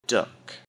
duck.mp3